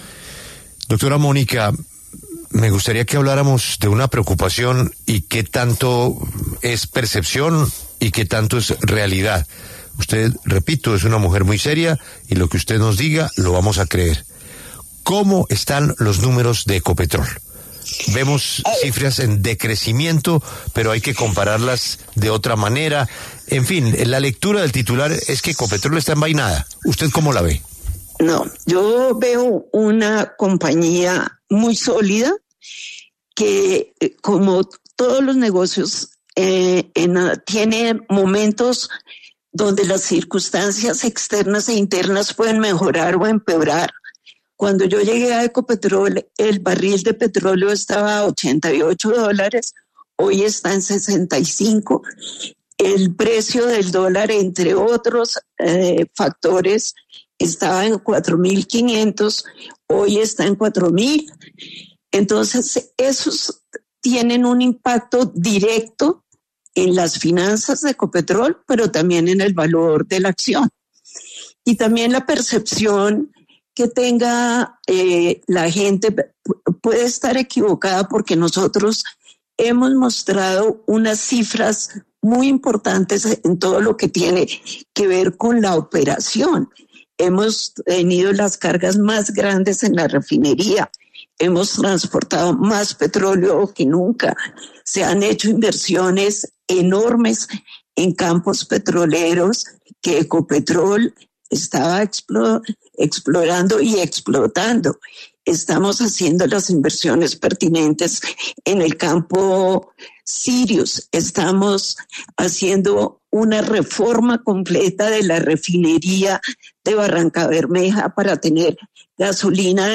La presidenta de la Junta Directiva de Ecopetrol, Mónica de Greiff, conversó con La W, entre otras cosas, sobre las finanzas de la petrolera.